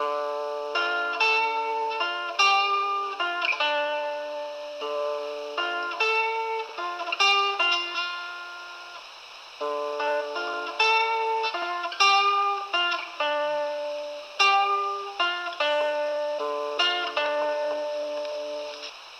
描述：模拟磁带声。
Tag: 50 bpm Acoustic Loops Guitar Electric Loops 3.23 MB wav Key : Unknown